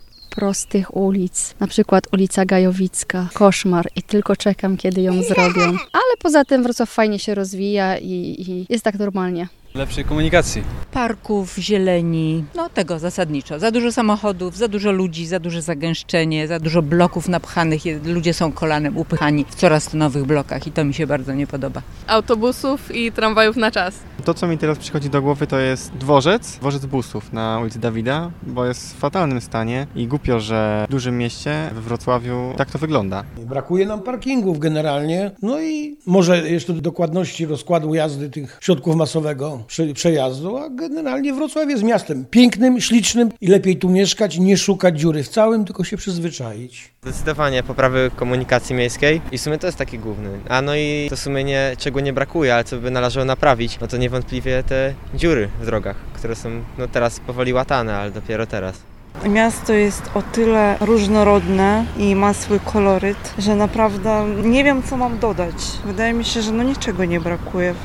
Zapytaliśmy wrocławian, czego brakuje im w mieście.